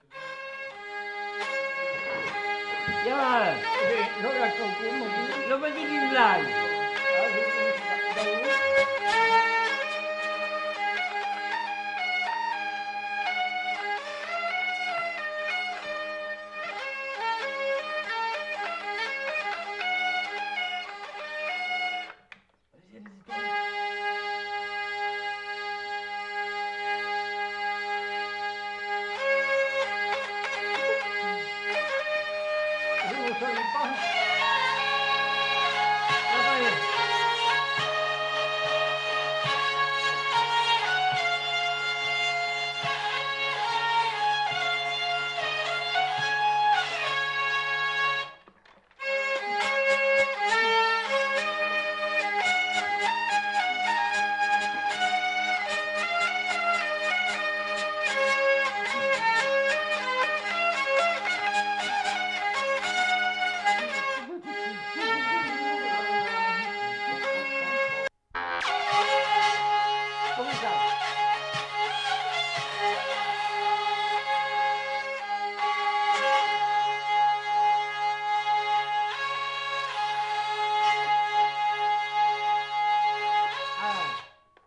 Lieu : Vielle-Soubiran
Genre : morceau instrumental
Instrument de musique : vielle à roue
Danse : valse